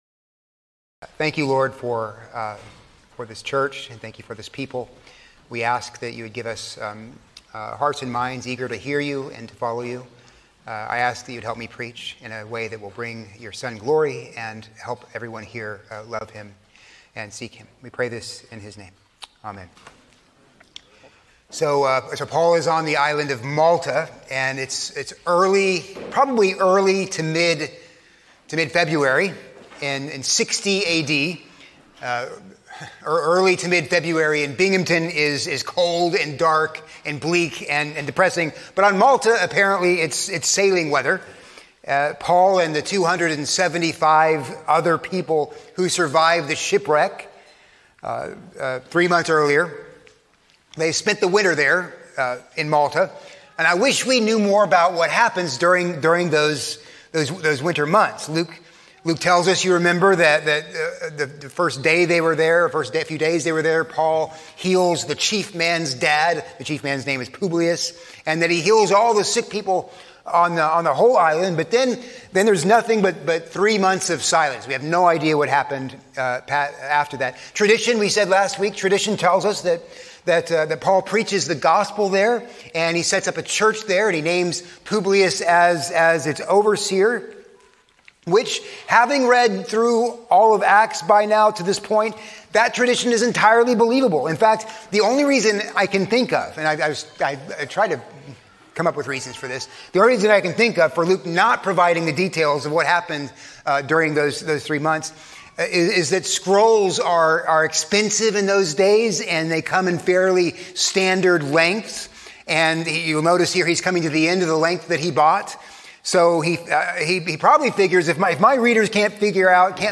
A sermon on Acts 28:11-22